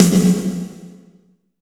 34 BIG SNR-L.wav